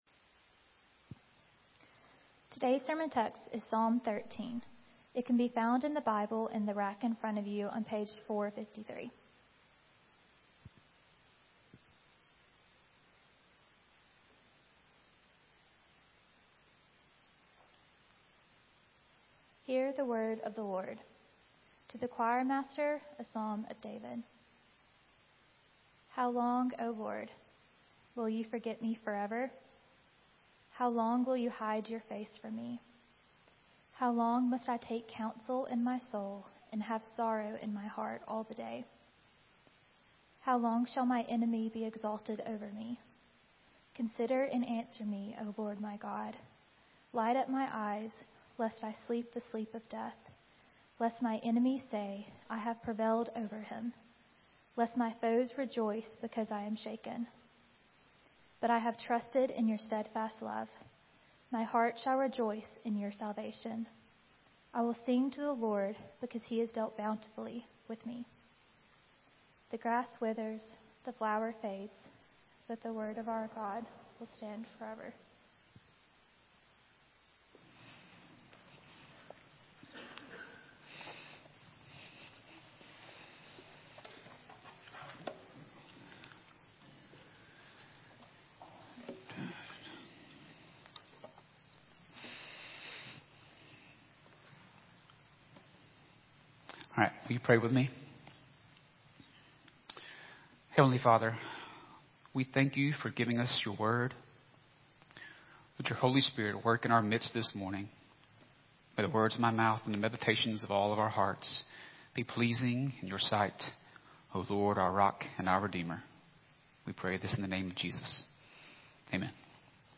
Here you will find the weekly sermons from Philadelphia Baptist Church in Vestavia Hills, Alabama.